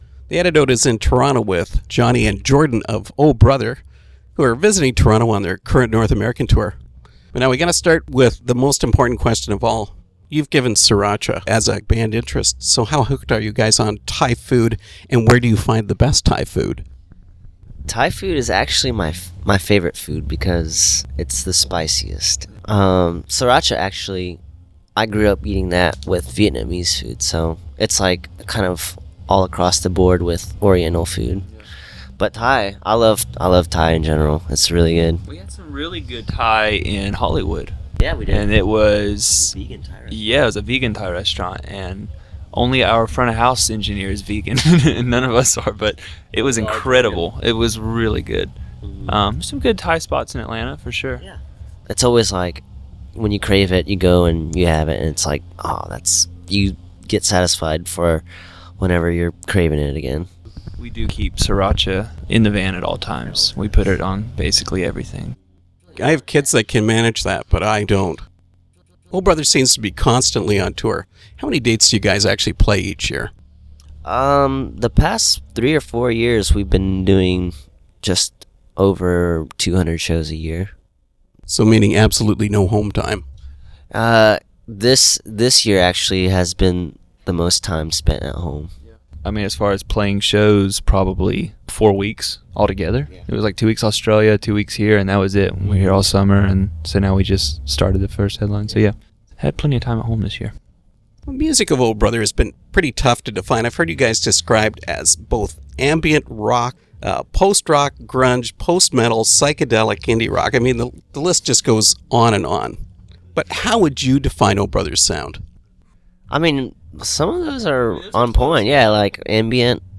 Interview with O’Brother